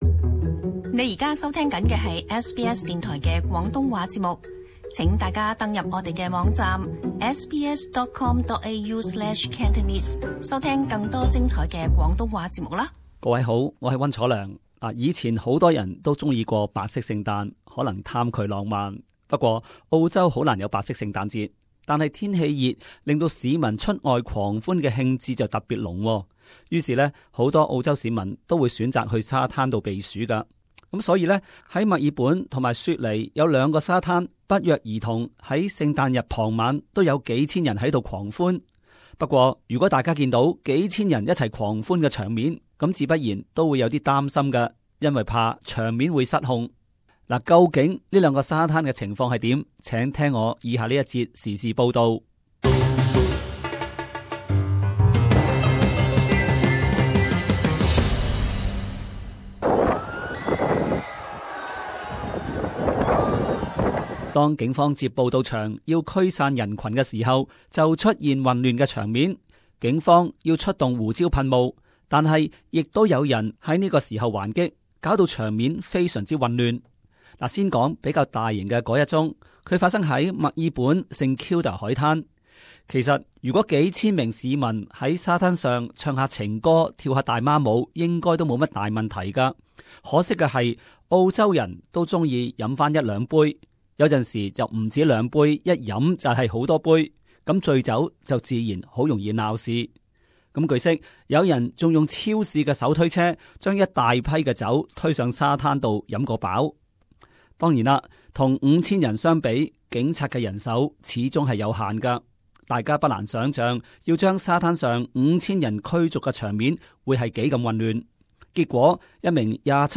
【時事報導】 墨爾本雪梨沙灘數千人灘醉酒鬧事